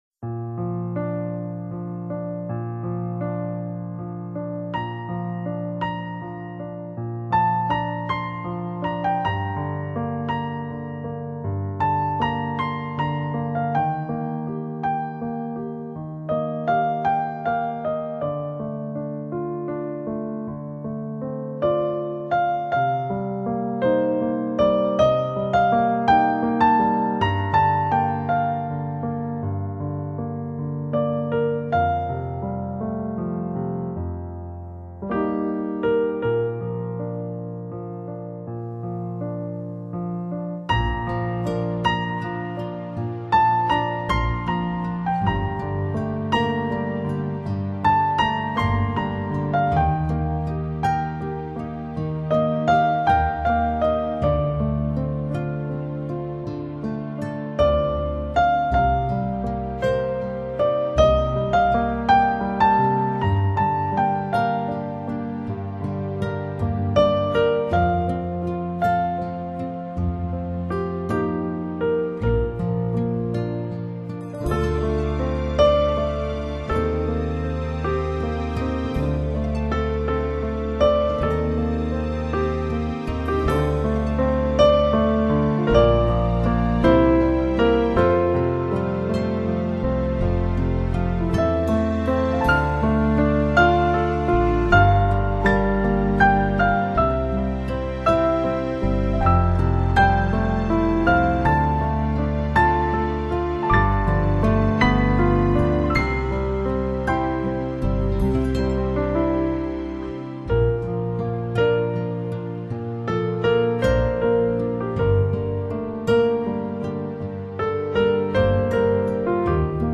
钢琴演奏